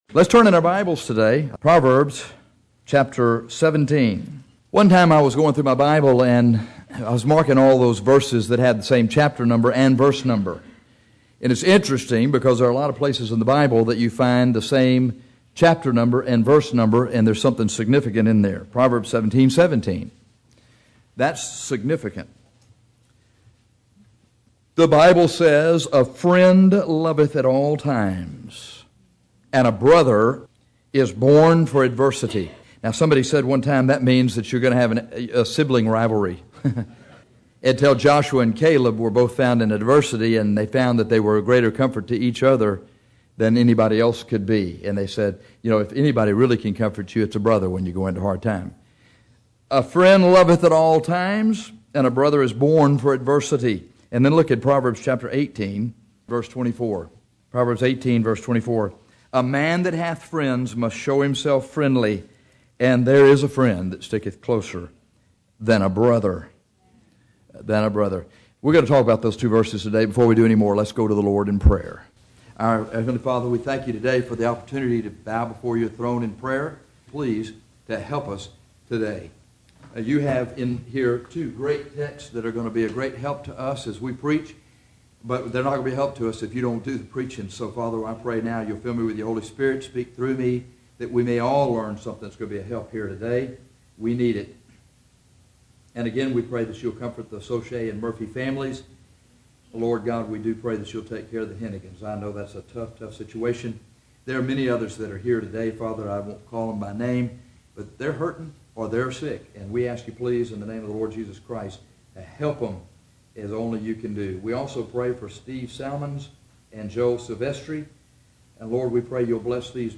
This sermon urges you to receive Jesus Christ, the greatest friend you will ever have, and to be the friend to others that Jesus is to you.